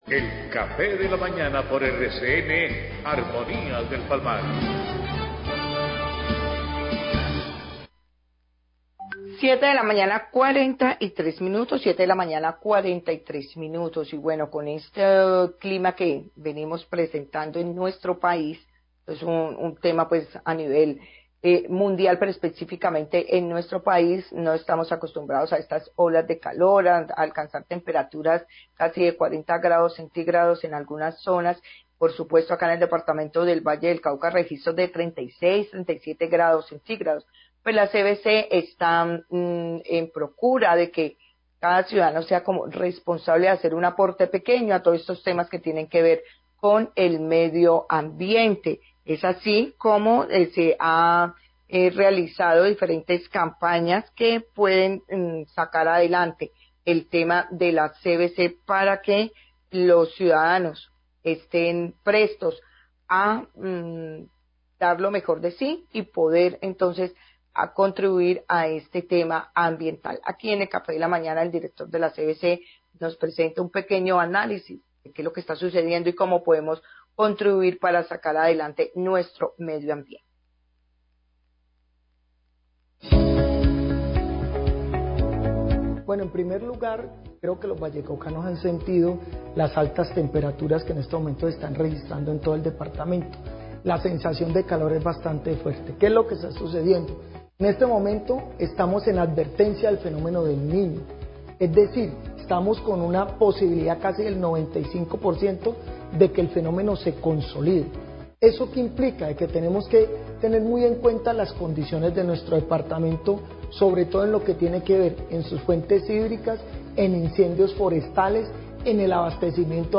Dir. general CVC habla de acciones para preservar recurso hídrico ante Fenómeno del Niño
Radio